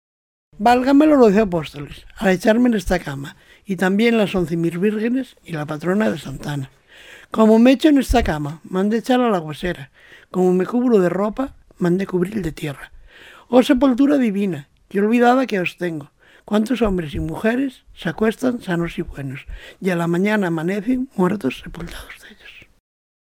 Clasificación: Oraciones
Lugar y fecha de recogida: Cervera del Río Alhama, 17 de febrero de 2002